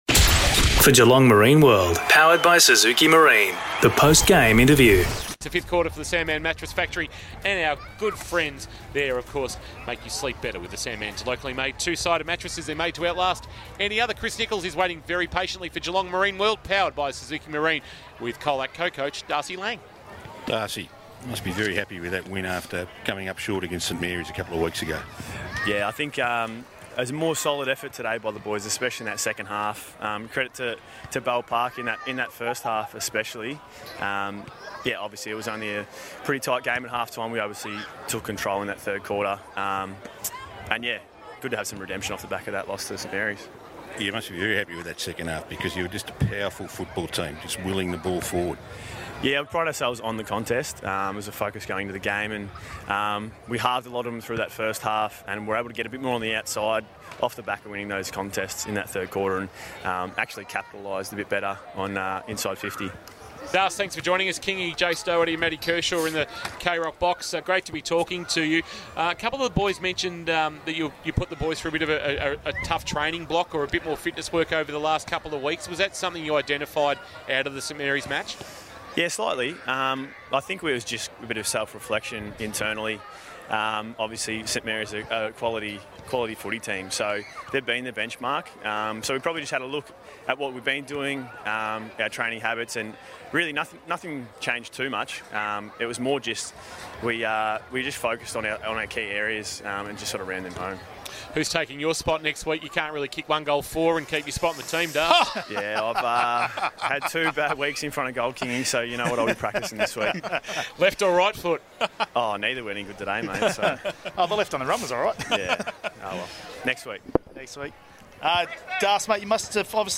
2022 - GFL ROUND 3 - BELL PARK vs. COLAC: Post-match Interview